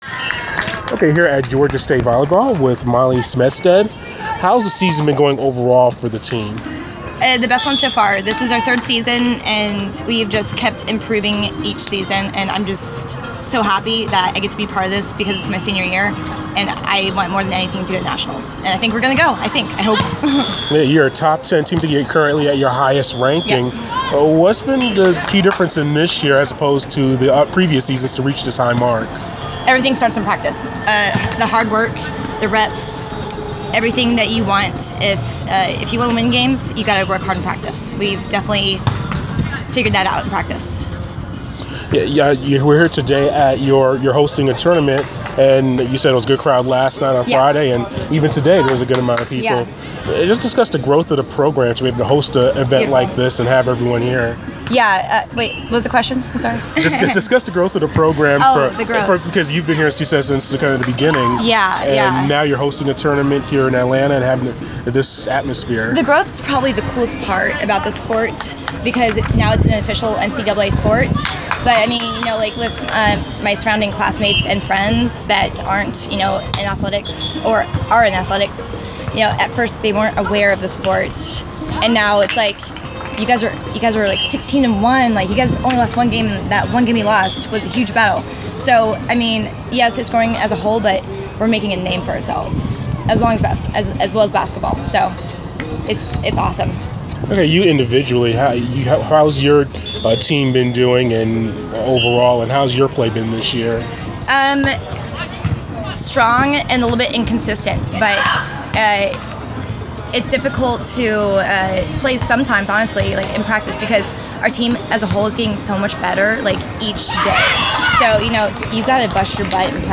Inside the Inquirer: Exclusive interview